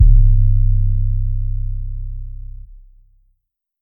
808 (See You Again).wav